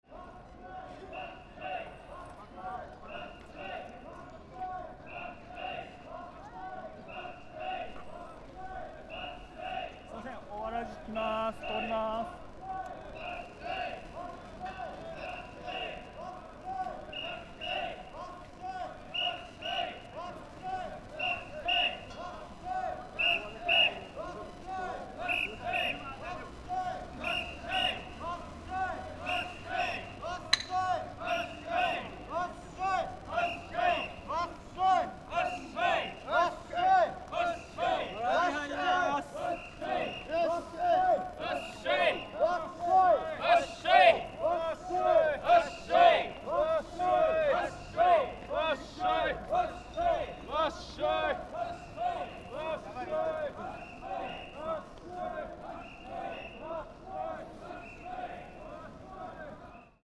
Fukushima Soundscape: Machi-naka Park
Machi-naka Square: 21 Febrary, 2025
Machi-naka Square (Entrance of the O-waraji (Japanese big sandal)(